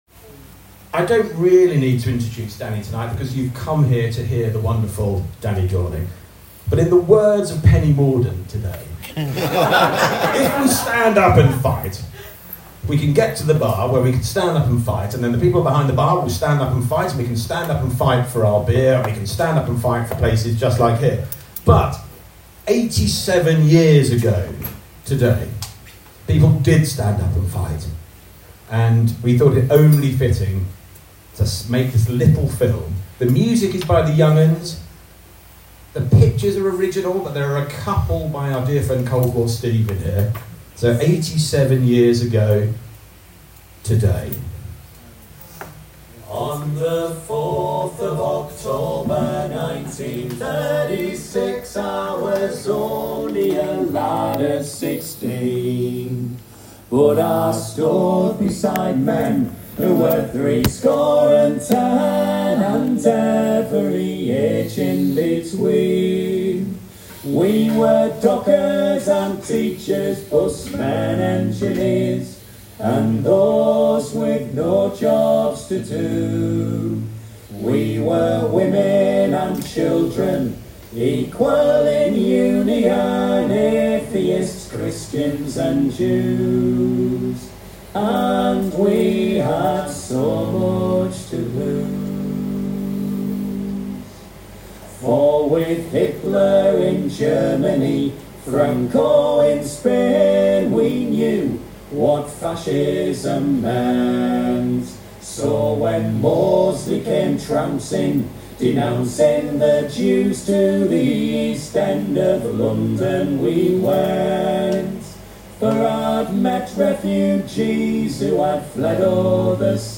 Danny Dorling talks about A Shattered Nation at The Wanstead Tap, London, 4 October 2023